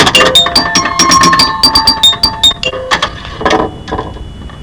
Commentaires : Flipper électronique sans carte son ,il possède dans la caisse un mini xylophone .Le son est ainsi très métallique, ce qui ne nuit en rien à la qualité de ce superbe flipper.Côté jouabilité, il possède de grands atouts comme une variété de cibles et de nombreux contacts étoiles.
Télécharger toute la gamme sonore du Flipper en fichier WAW ( 99.9 ko )